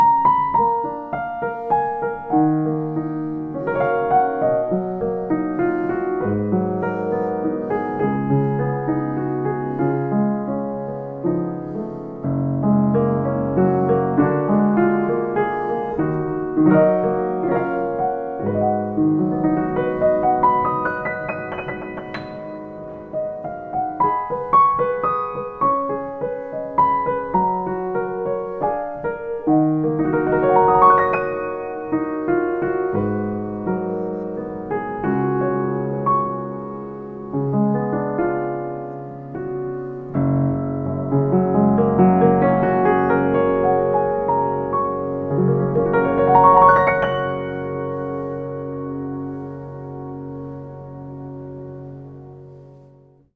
Prachtige volle klank en geweldige aanslag.